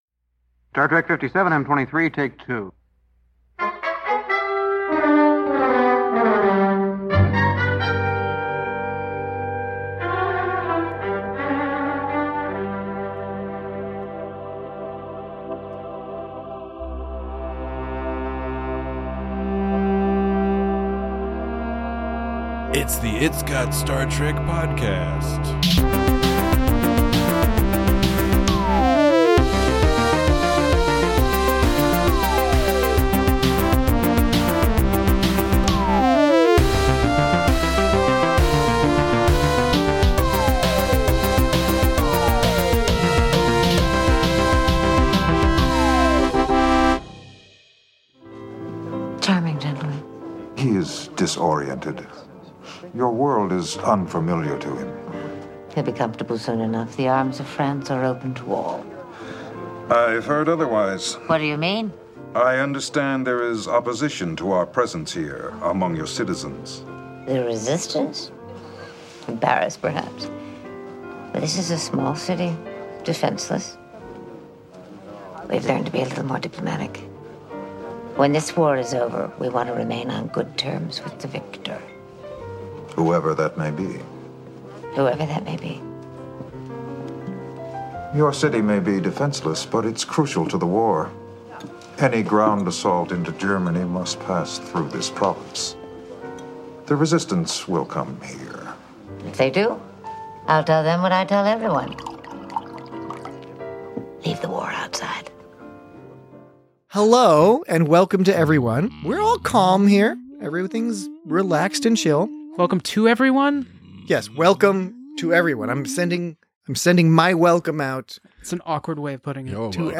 Join your very dry hosts as they discuss Voyager's penchant for combining well-structured traditional moral tales with clever sci-fi conundrums, how much fun many of (but not all) the actors seem to be having playing different characters, and the joyfully chaotic imagery of Klingons slashing Nazis to bits.